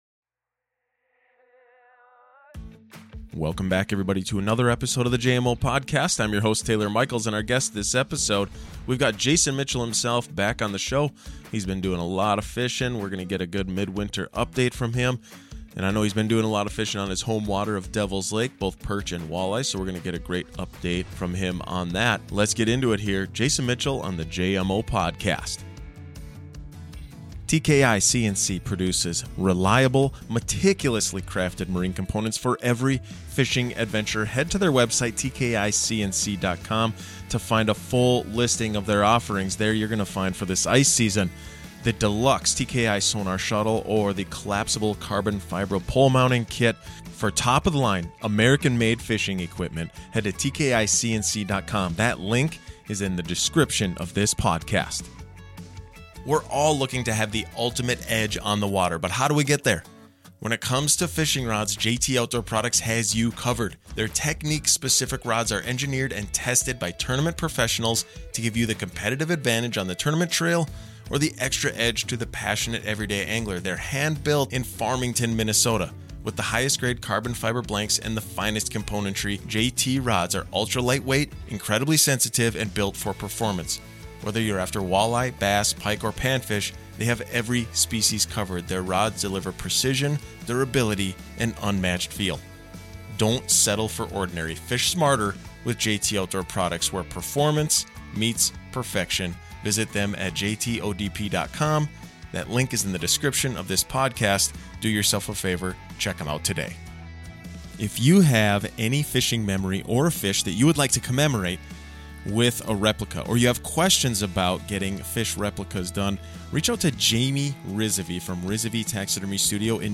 Low numbers of perch on Devils Lake has been a hot topic for ice anglers this season but what you might not be hearing about is the exceptional walleye opportunities that are happening right now. In this interview